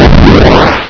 explosion3.wav